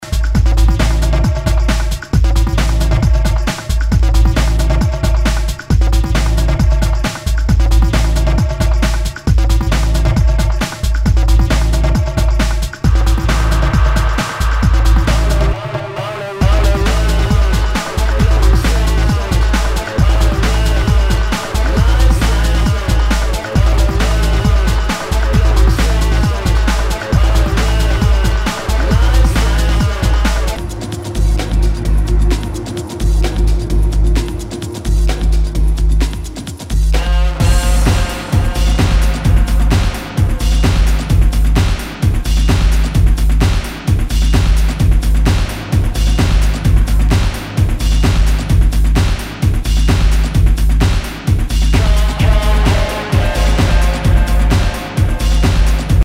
Nu- Jazz/BREAK BEATS
ナイス！ファンキー・ブレイクビーツ！！